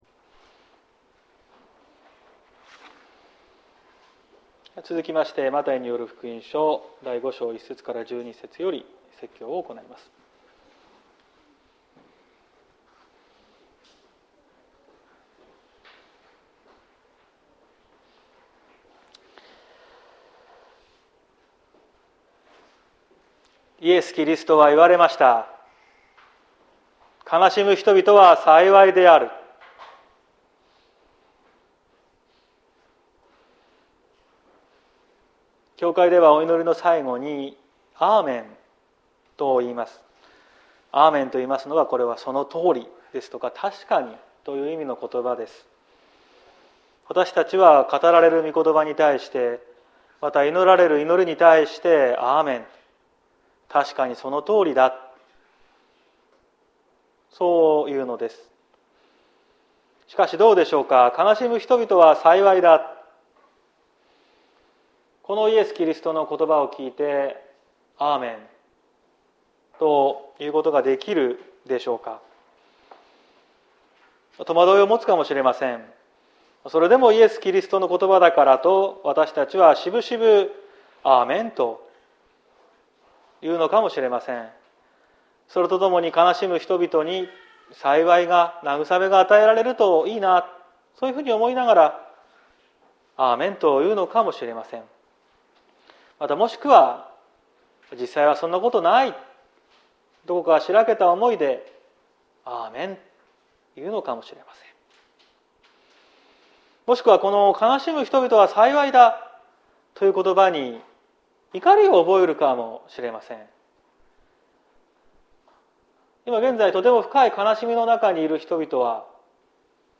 2022年06月19日朝の礼拝「悲しみの先に」綱島教会
綱島教会。説教アーカイブ。